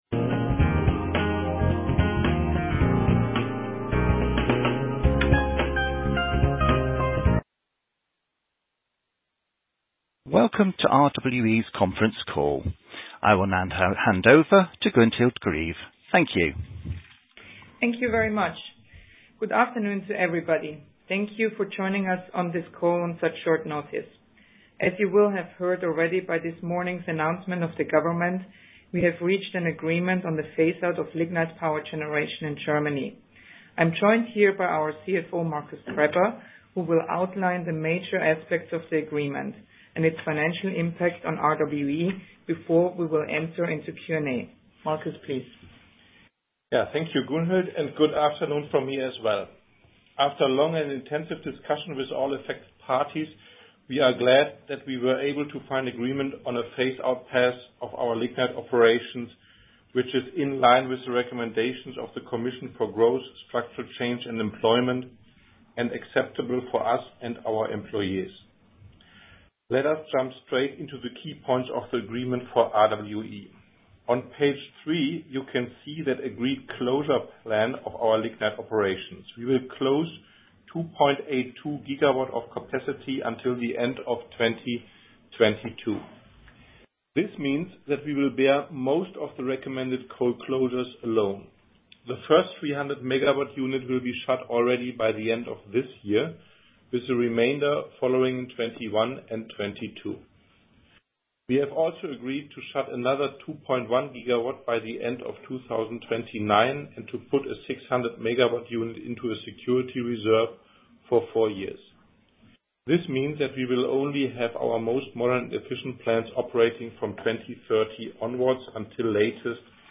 Investoren- und Analysten-Telefonkonferenz zum Braunkohleausstieg
Investoren und Analysten-Telefonkonferenz mit Markus Krebber zum Braunkohleausstieg